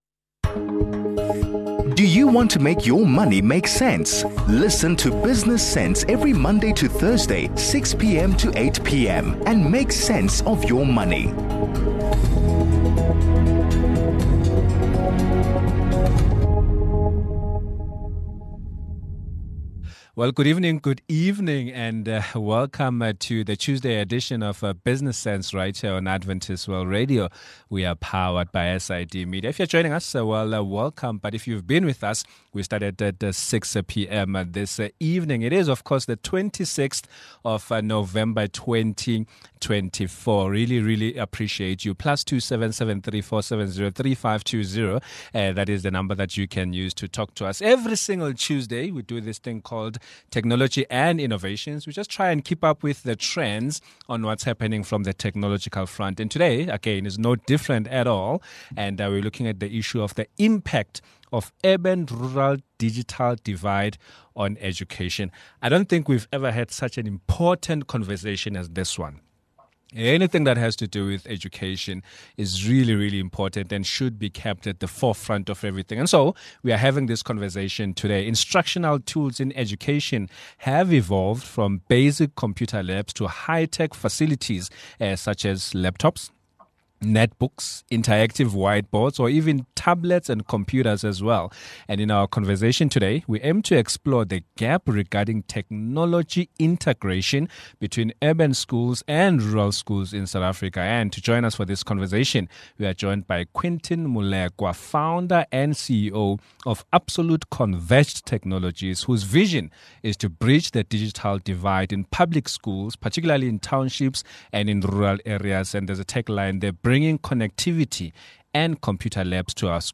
In our conversation